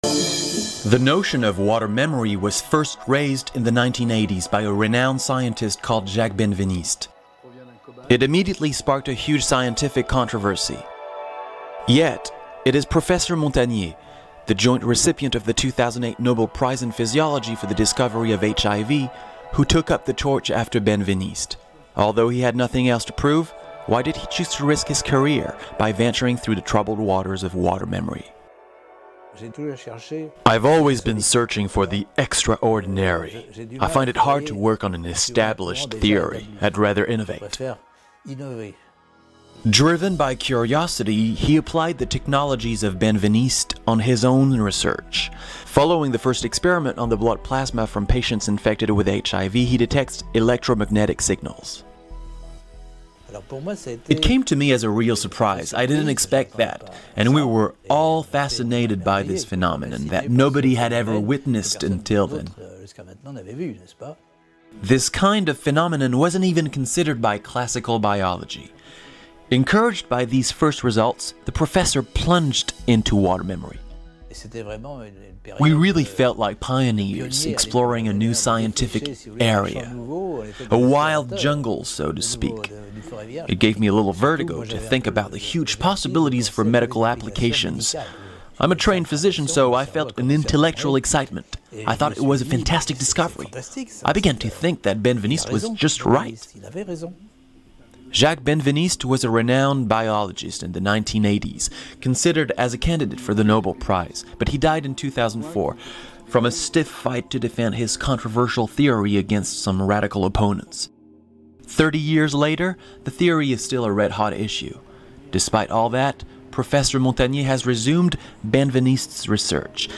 Water Memory (2014 Documentary about Nobel Prize laureate Luc Montagnier)